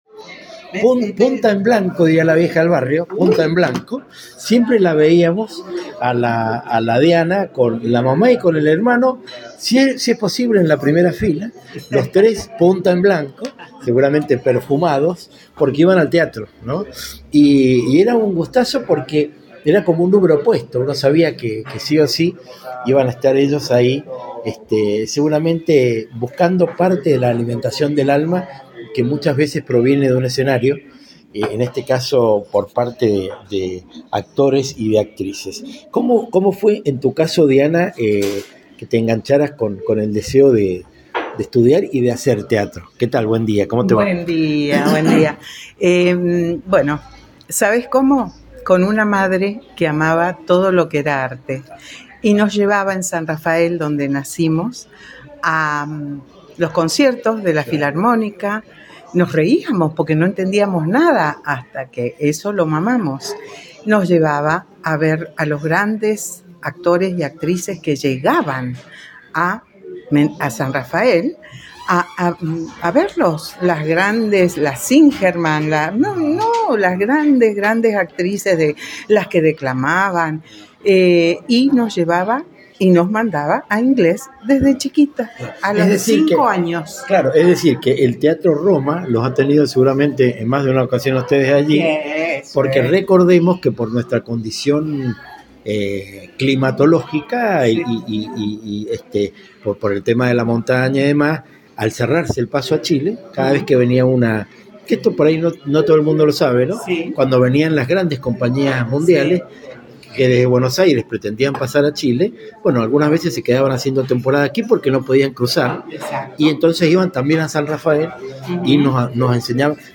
Entrevistas Latinocracia
Si esta mujer y yo hicimos bien la tarea, te darán ganas de formar parte de ese momento en el que dialogamos al borde de una taza con café.